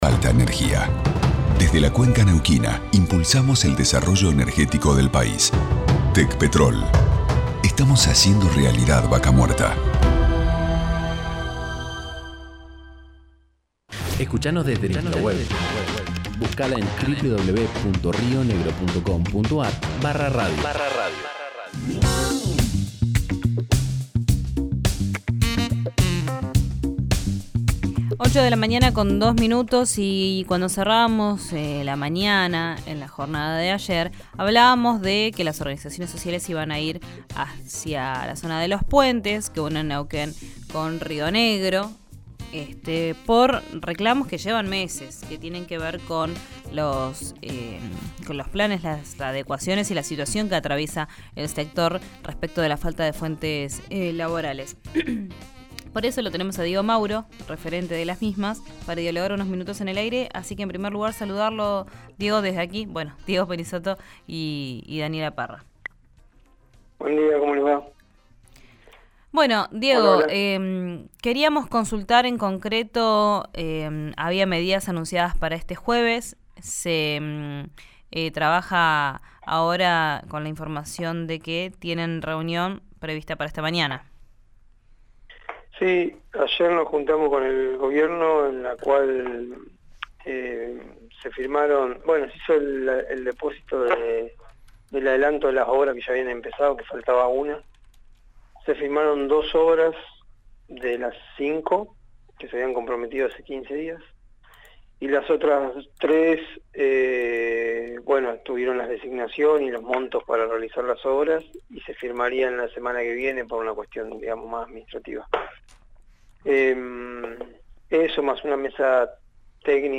en RÍO NEGRO RADIO